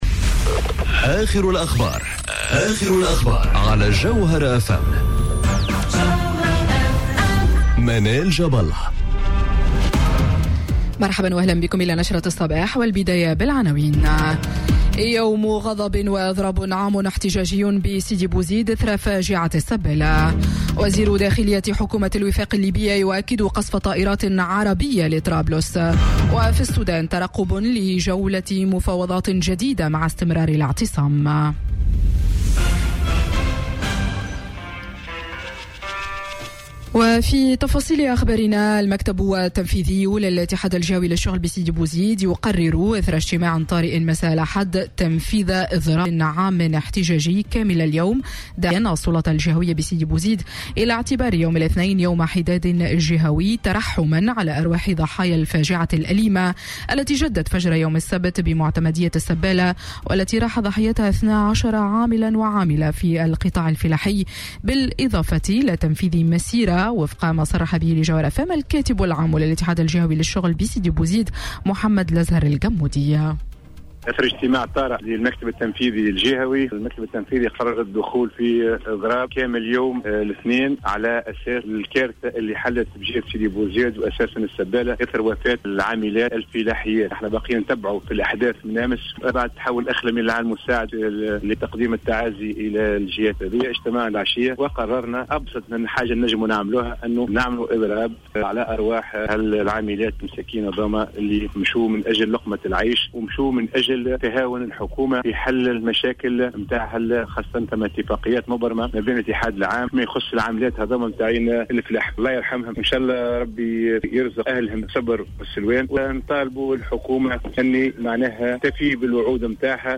نشرة أخبار السابعة صباحا ليوم الإثنين 29 أفريل 2019